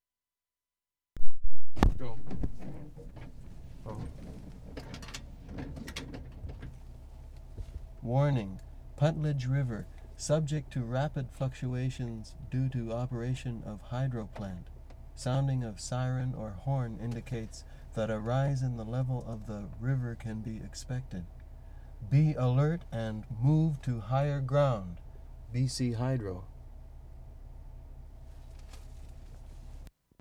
VANCOUVER ISLAND March 15, 1973
PUNTLEDGE PARK, reading warning sign 0'25"